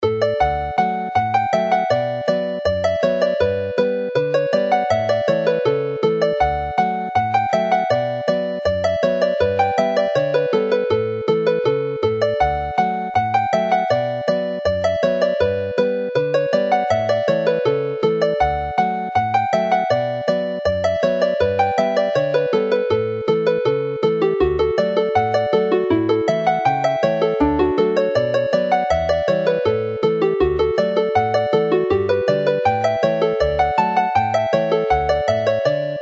Alawon Cymreig - Set Dowlais - Welsh folk tunes to play
Dowlais Hornpipe (as a reel)